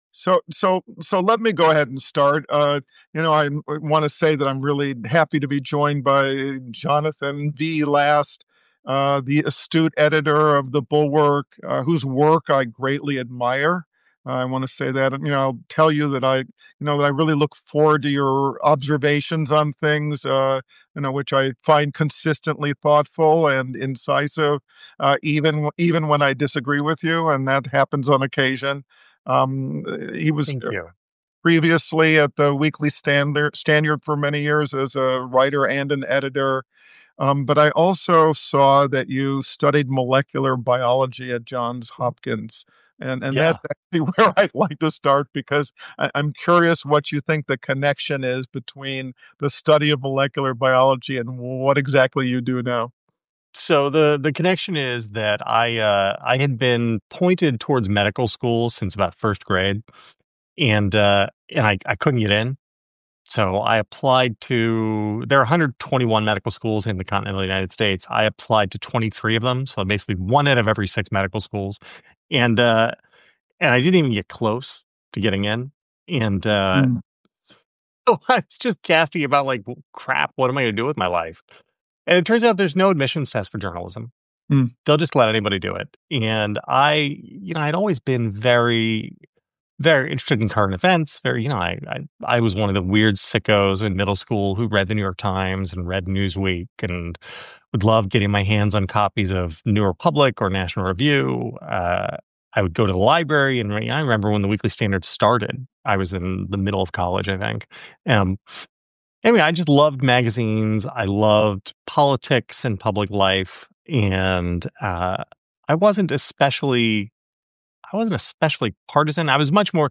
Recorded live on Friday, November 8, 2025